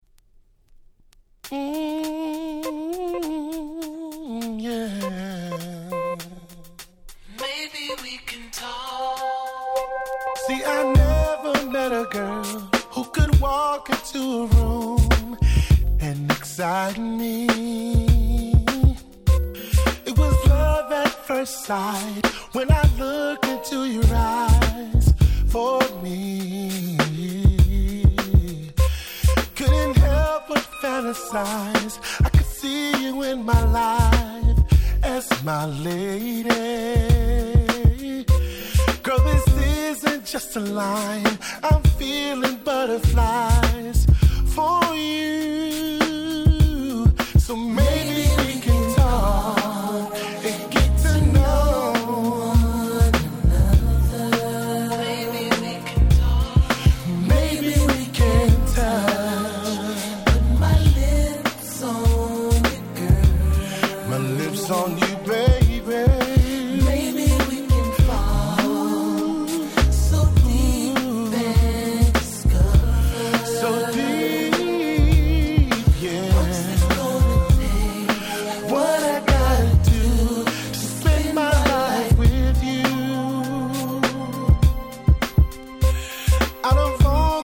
【Media】Vinyl 12'' Single
05' マイナーR&B良曲！！